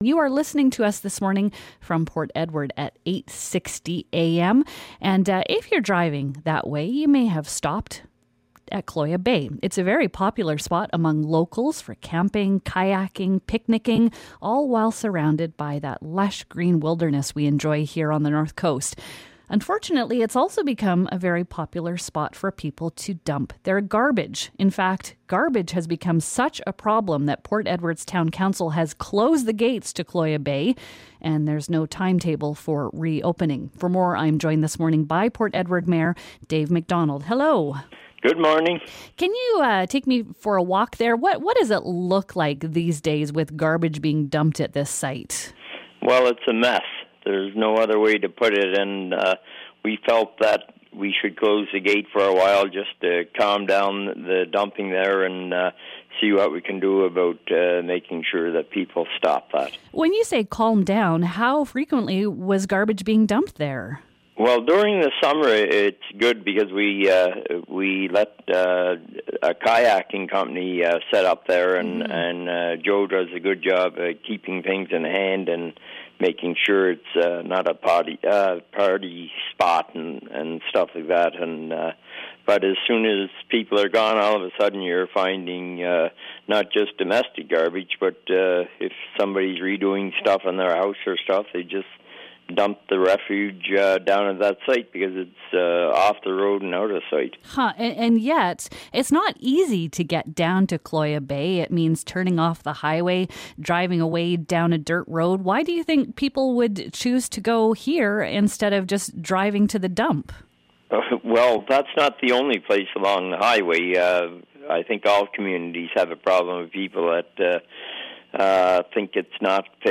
But it's also a popular spot for people to dump their trash. In fact, garbage has become such a problem that Port Edward council has closed the gates to the bay. We speak to mayor Dave MacDonald.